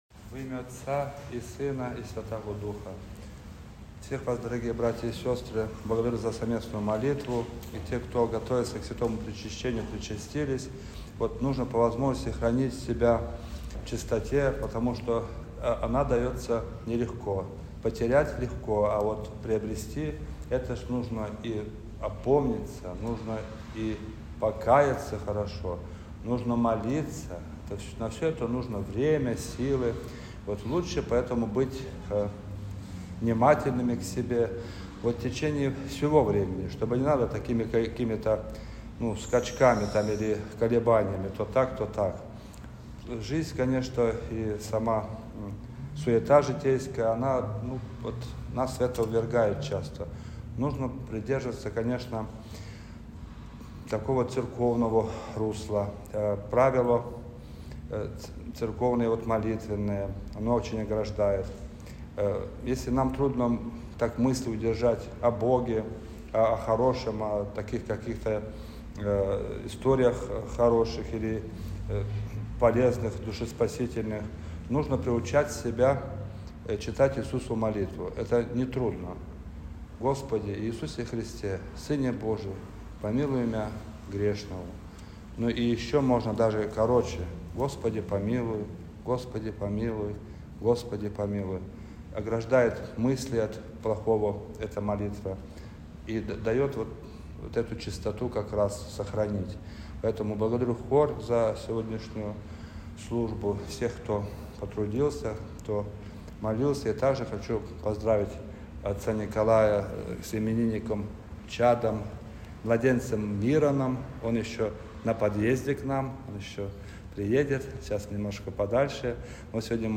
Проповедь-1.mp3